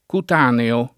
cutaneo [ kut # neo ]